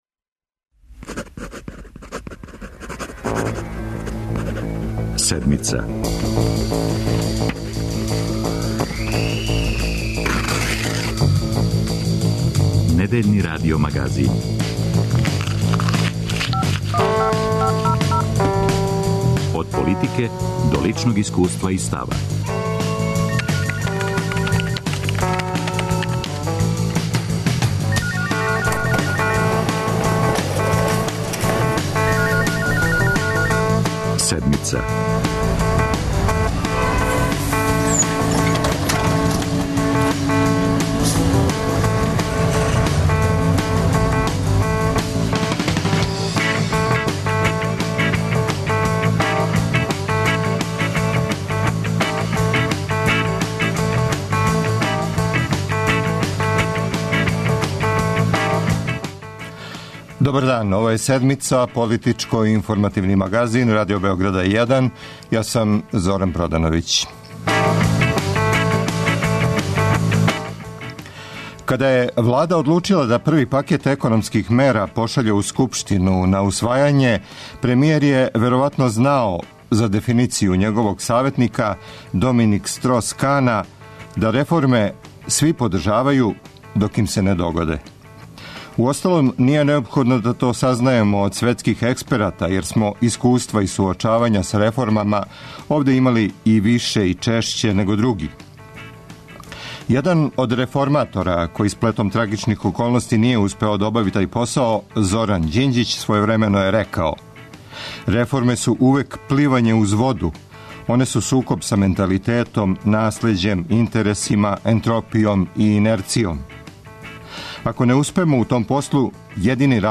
Реформски закони у парламенту, радници пред Скупштином. За Седмицу говоре социолог